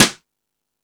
Index of /kb6/E-MU_Pro-Cussion/jazz drums
Jazz Drums(05).wav